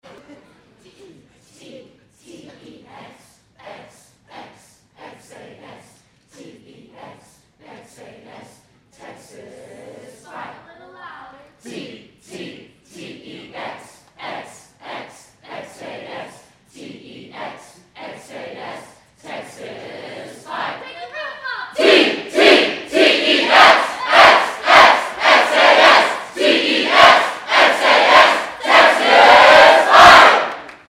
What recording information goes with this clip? The Big Yell! – Recording old UT cheers from the 1890s – 1900s.